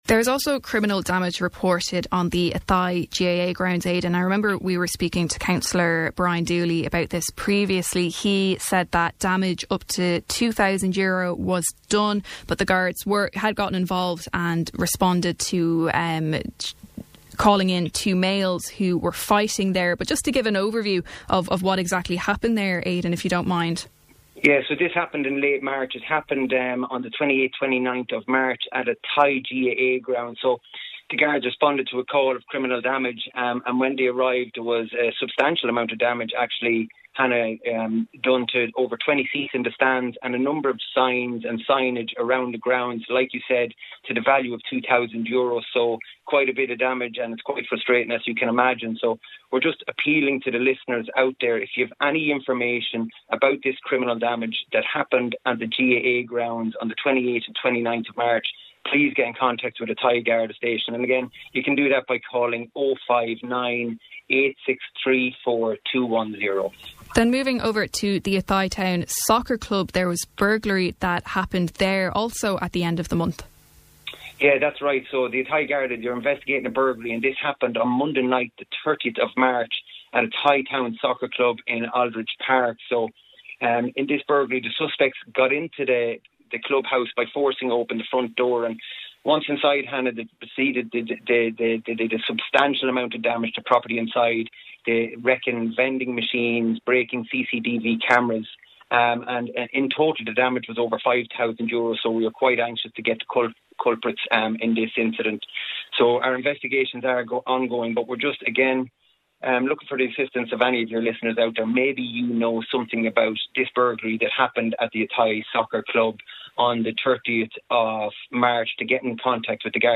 Speaking on this mornings Kildare Today show